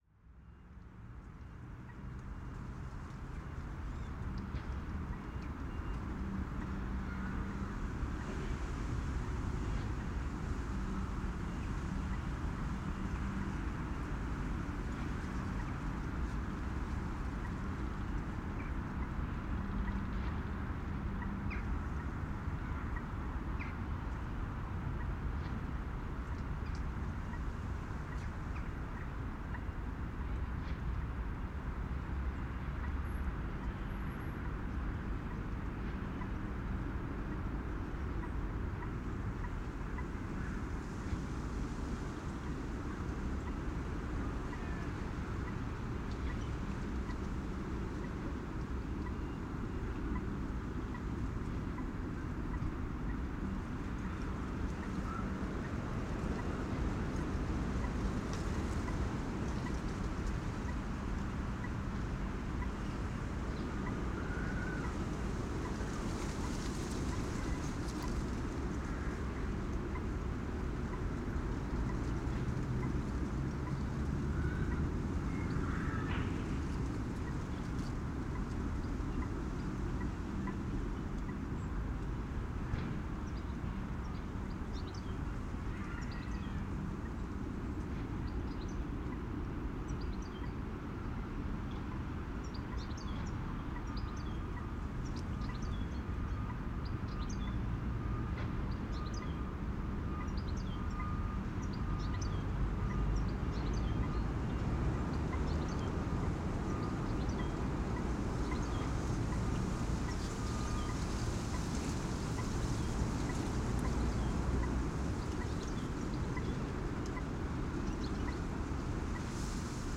naast_begraafplaats_15_30.wav